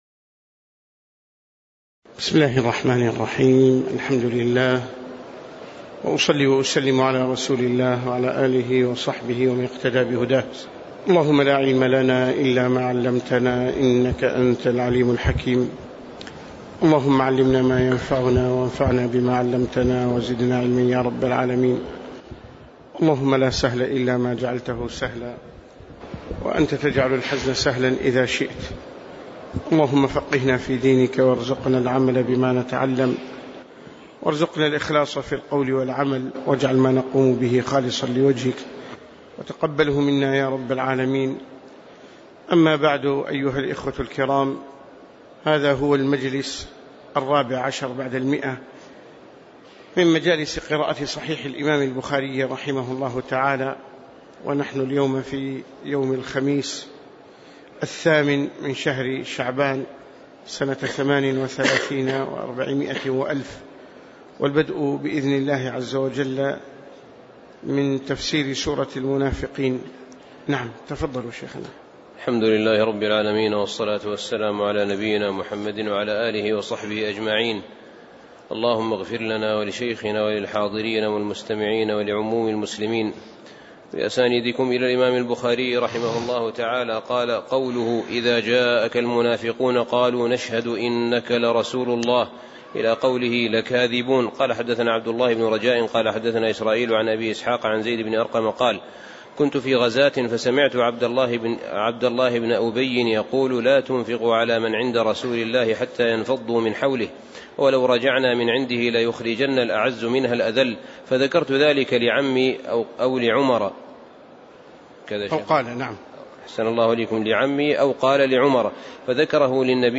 تاريخ النشر ٨ شعبان ١٤٣٨ هـ المكان: المسجد النبوي الشيخ